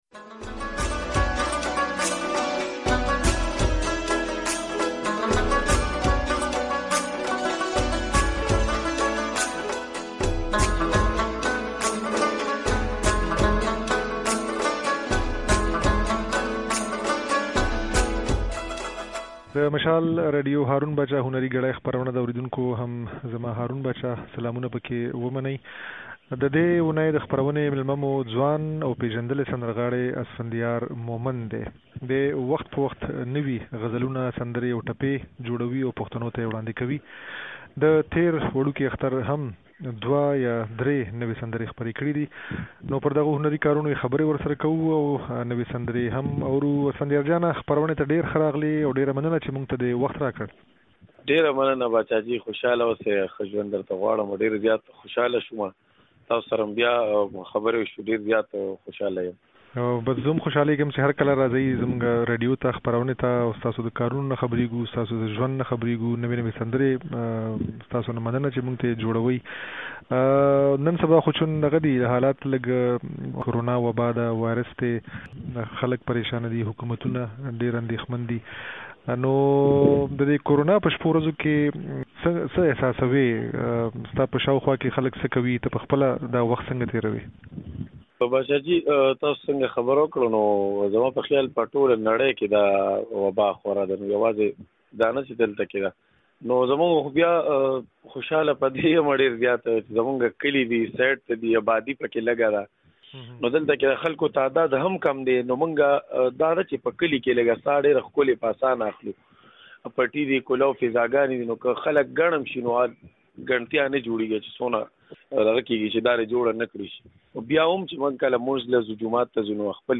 د دې اوونۍ په "هارون باچا هنري ګړۍ" خپرونه کې د ځوان او پېژندلي سندرغاړي اسفنديار مومند د هنري کارونو په اړه د ده خبرې او څو نوې سندرې اورئ. مومند وايي، له رباب سره يې شوق وو خو وروسته يې وغوښتل چې سندره ووايي.